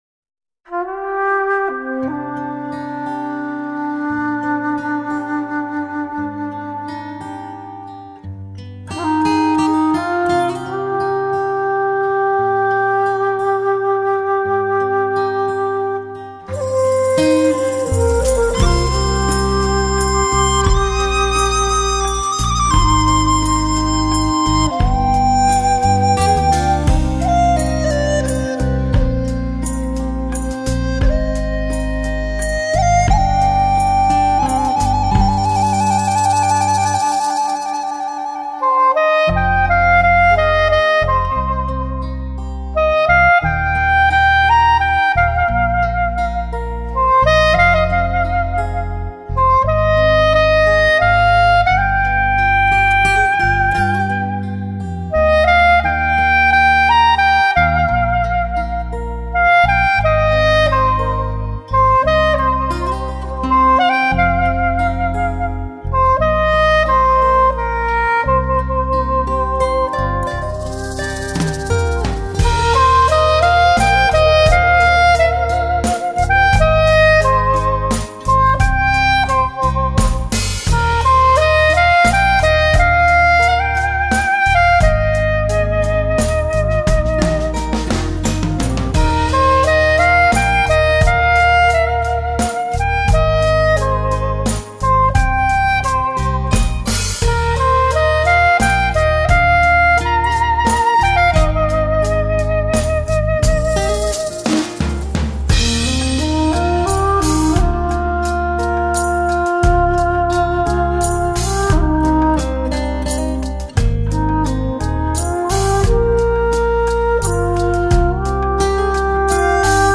类型: 轻音乐
至纯音色，如实质感栩栩如生，触手可及，全面提升细节定位，用无以伦比的空气感和层次感，再次诠释发烧天碟的定义。